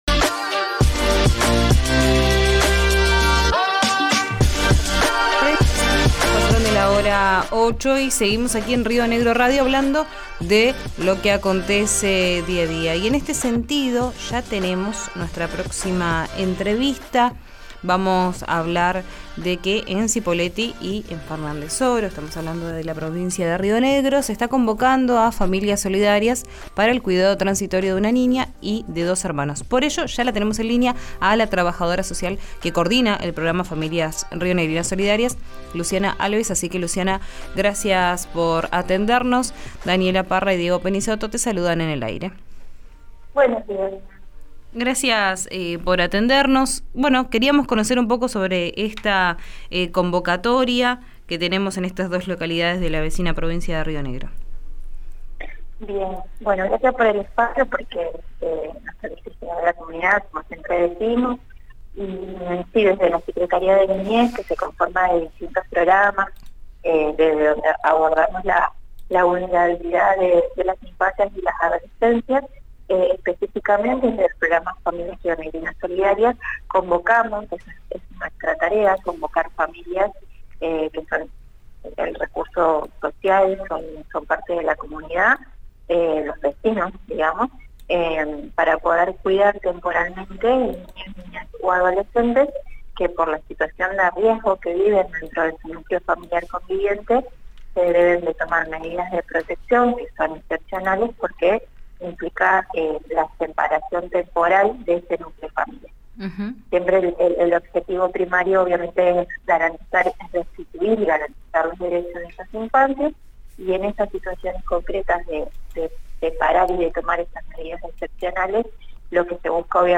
Desde la secretaría de niñez, adolescencia y familia explicaron los requisitos en RÍO NEGRO RADIO.